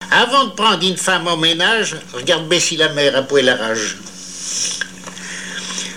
Langue Patois local
Genre dicton
émission La fin de la Rabinaïe sur Alouette